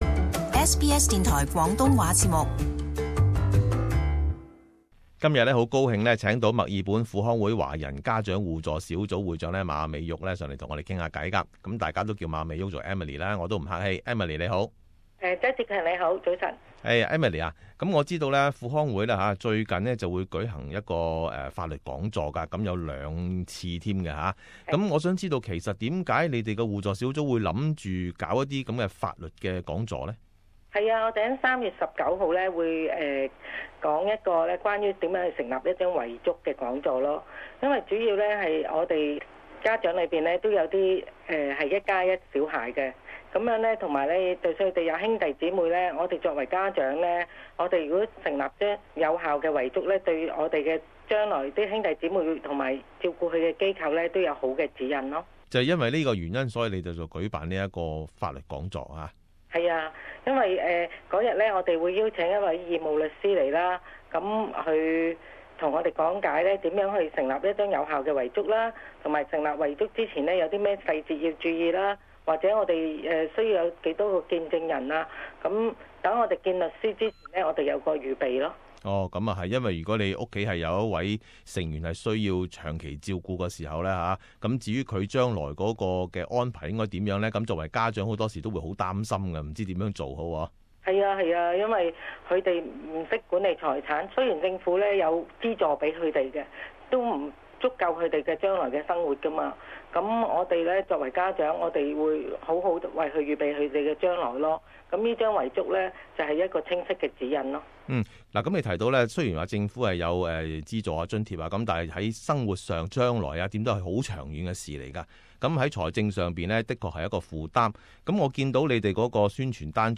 【社團訪問】 墨爾本輔康會即將舉行法律講座